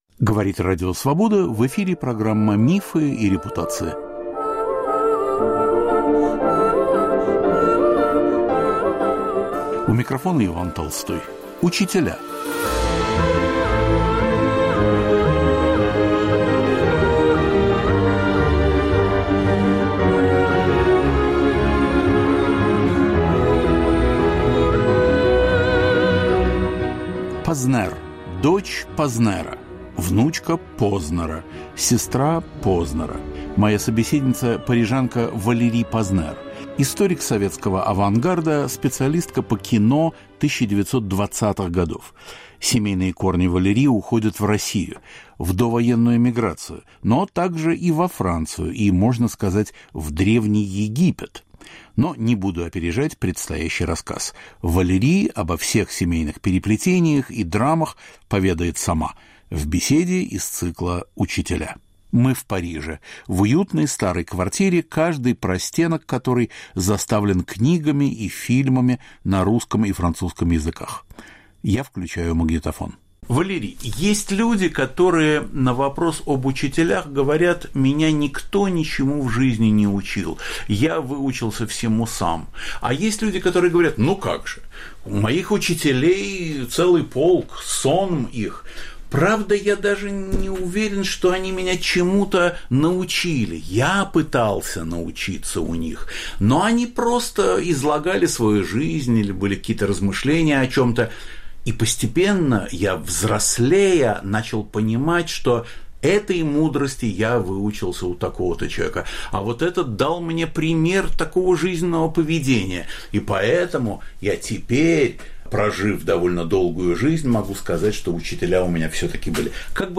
Беседа с французской исследовательницей советского кино и художественного авангарда.